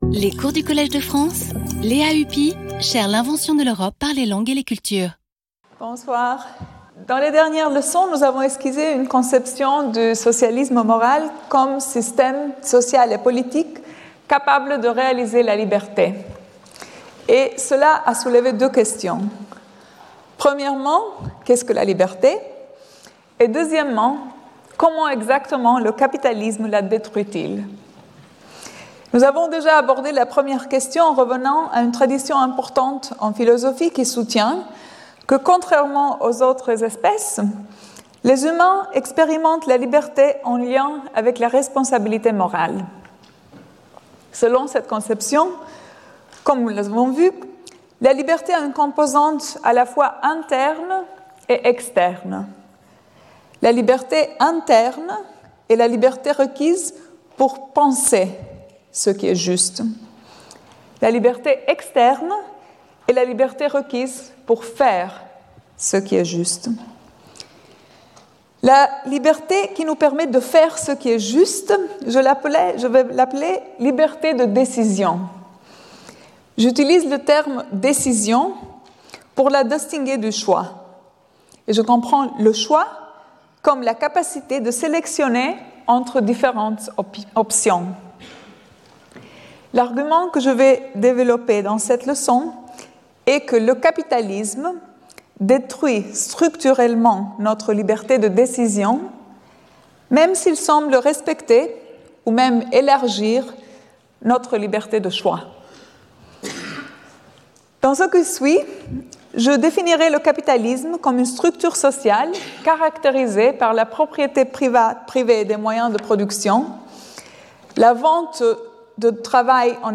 Lea Ypi Professor of Political Theory at the London School of Economics and Political Science, Visiting Professor at the Collège de France
Lecture